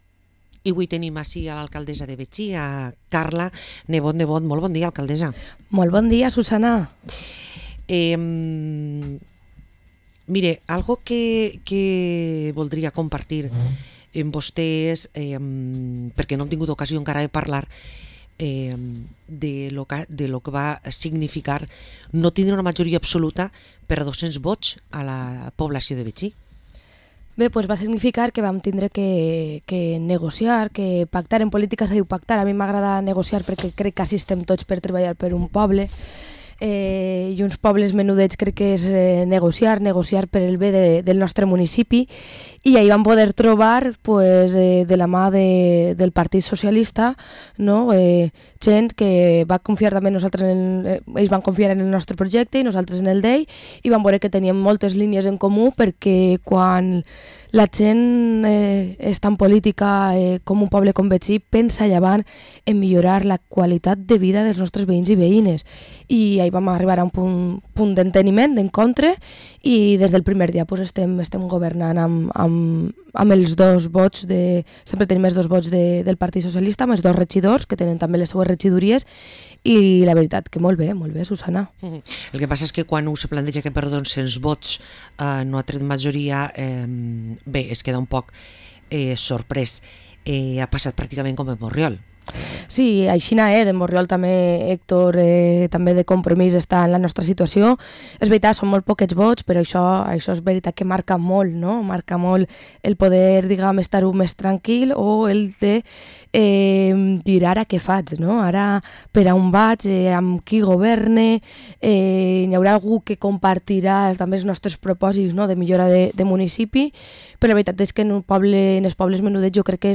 Parlem amb Carla Nebot, Alcaldessa de Betxí